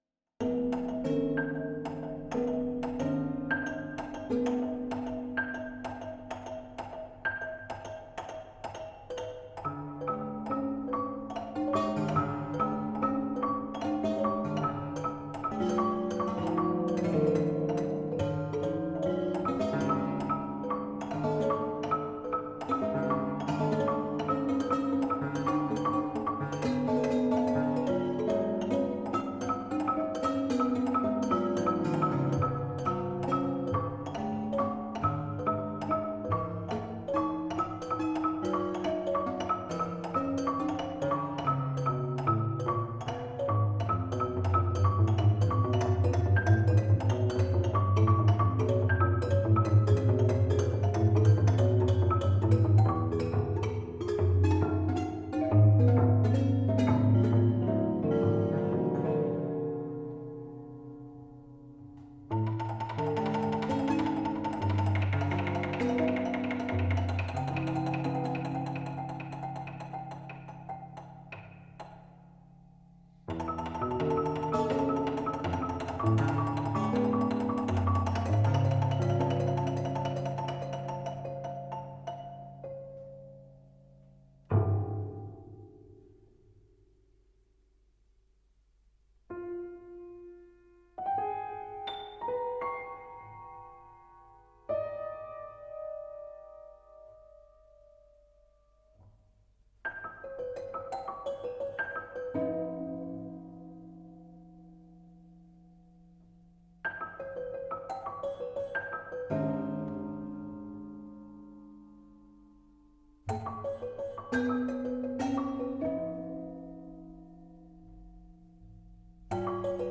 composition et piano